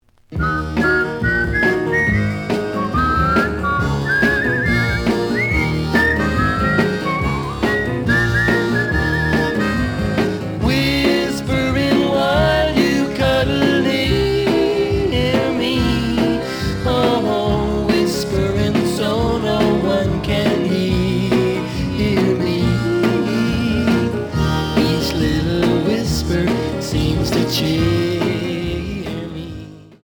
試聴は実際のレコードから録音しています。
●Genre: Rock / Pop
●Record Grading: EX- (盤に若干の歪み。多少の傷はあるが、おおむね良好。)